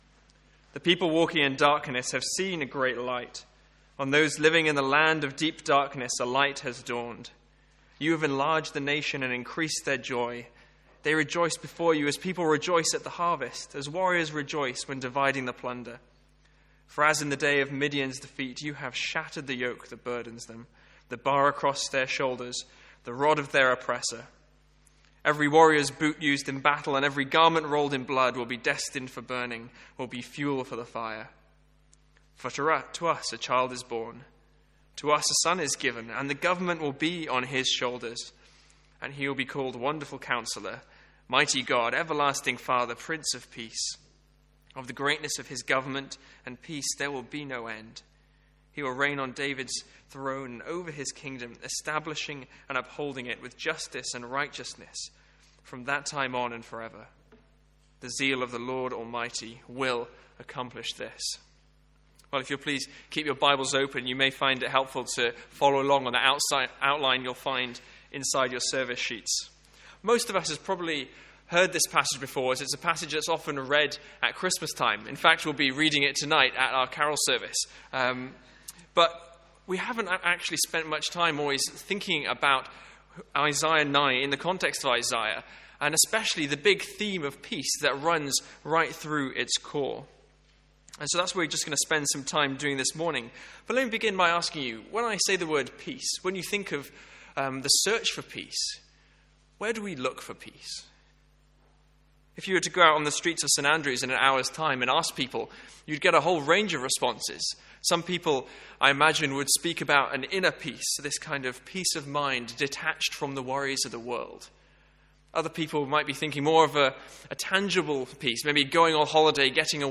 A one off Sunday morning sermon on Isaiah 9:2-7.